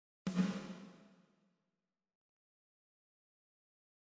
On utilise le réglage de balance (MIX) entre le son sec (dry) et affecté (wet).
Ici la source s'éloigne :